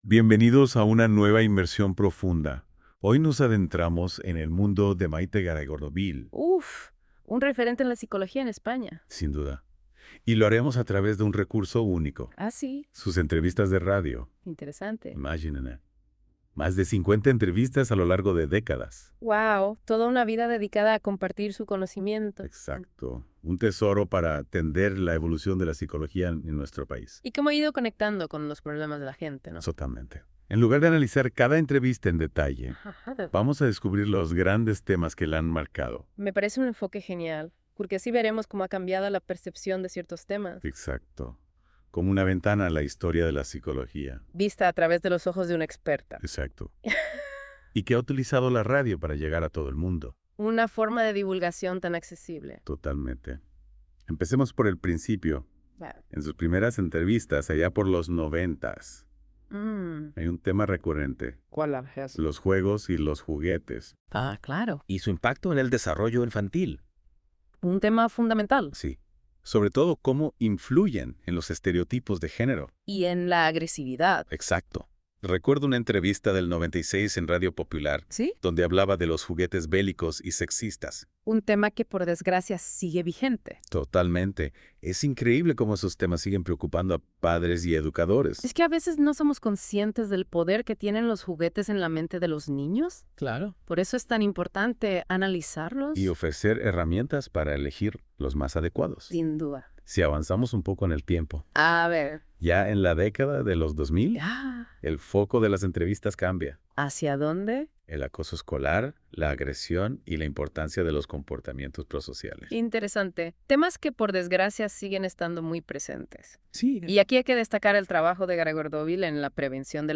ENTREVISTAS EN RADIO